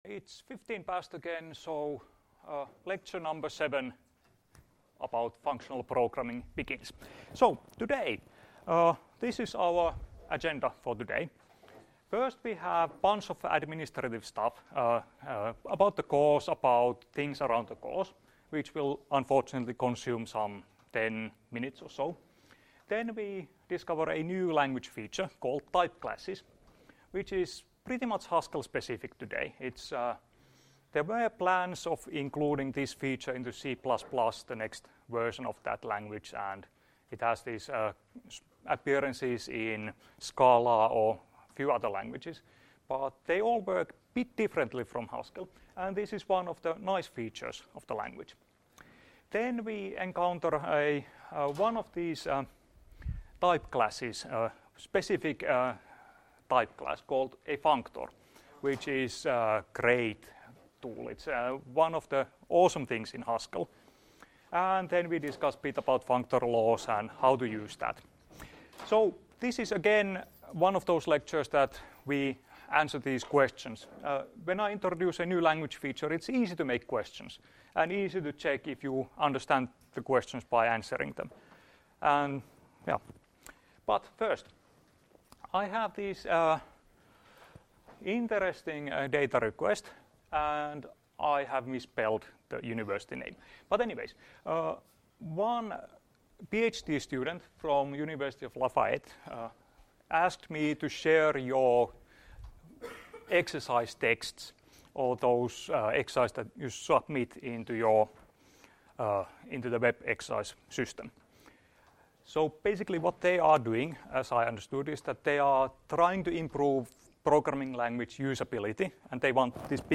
Luento 20.2.2017 — Moniviestin